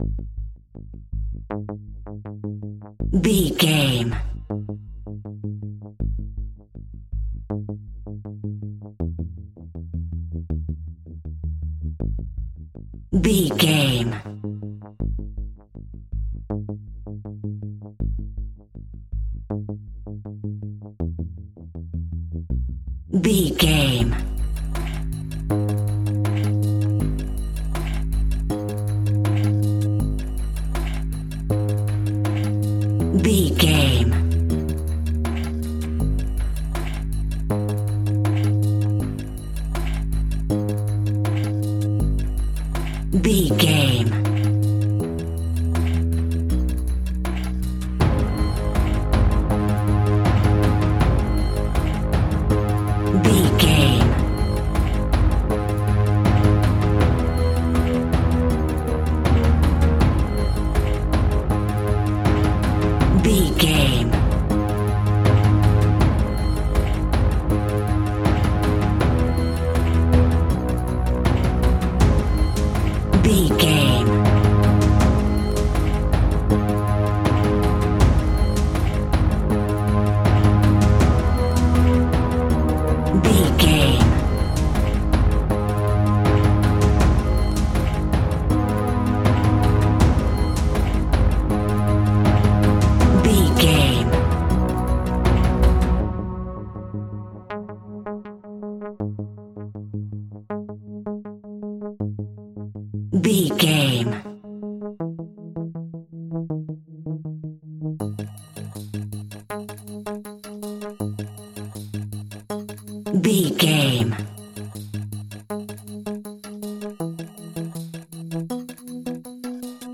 Aeolian/Minor
G#
ominous
dark
disturbing
eerie
driving
synthesiser
brass
percussion
instrumentals
horror music